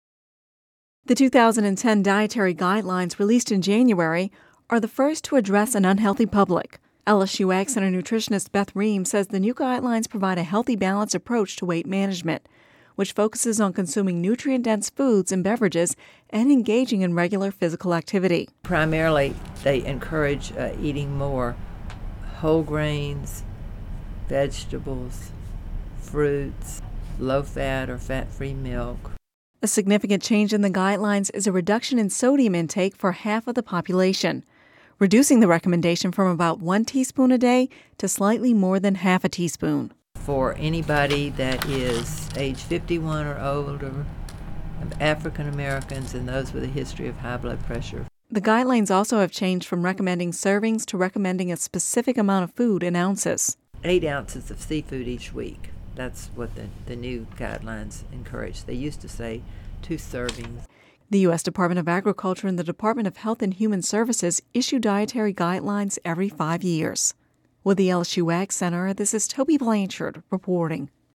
(Radio News 03/21/11) The 2010 Dietary Guidelines, released in January, are the first to address an unhealthy public.